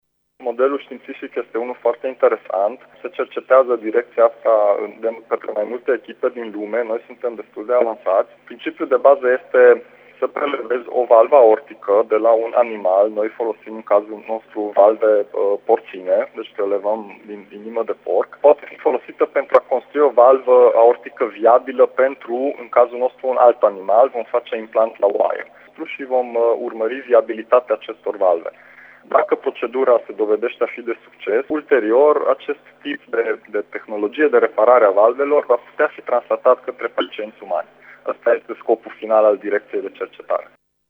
Conducerea Universităţii a anunţat azi, într-o conferinţă de presă, demararea unui proiect de cercetare pentru dezvoltarea unor biomateriale care să ducă la regenerarea valvelor cardiace umane, în valoare de peste 8,7 milioane de lei, care se va derula în următoarele 48 de luni în cadrul Centrului Avansat de Cercetări Medicale şi Farmaceutice.